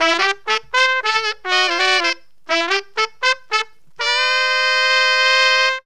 HORN RIFF 20.wav